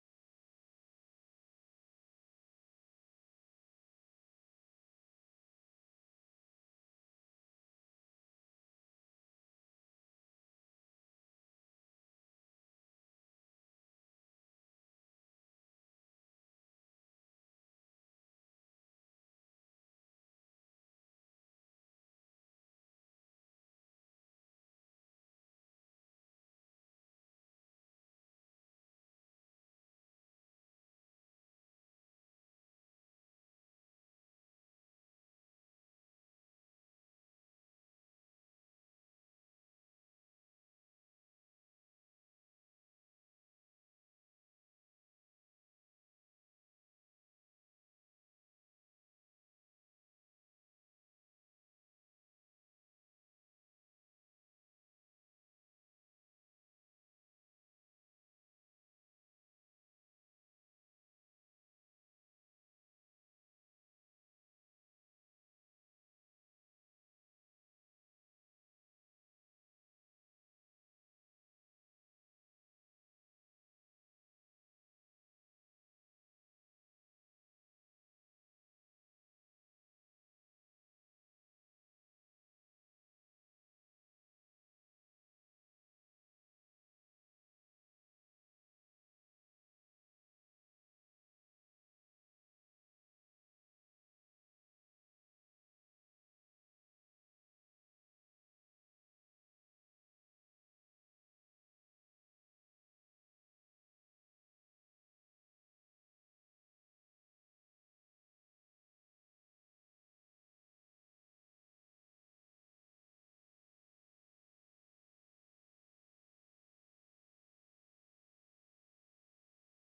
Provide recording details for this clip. Matthew 15:1-20 Service Type: Morning Service True spirituality cannot be reduced to mere tradition or ritual.